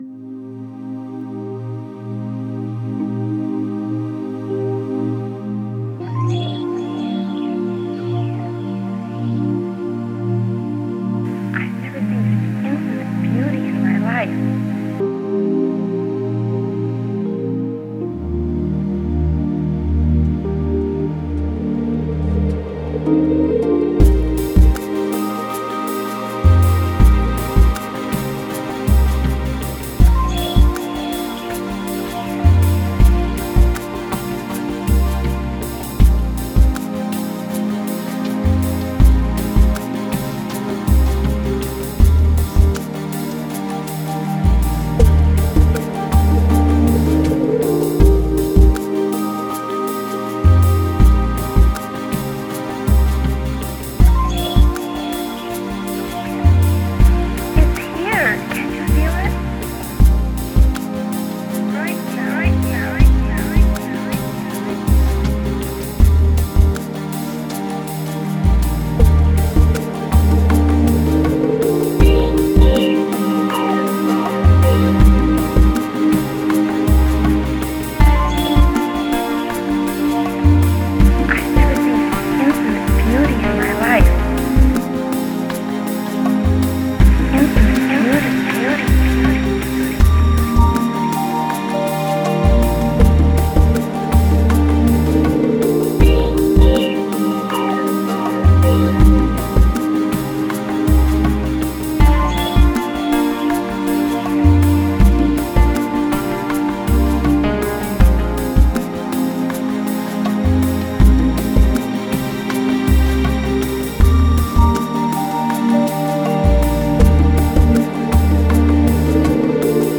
Жанр: Electronic, Downtempo, Chill Out, Lounge